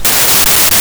hurt2.wav